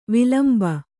♪ vilamba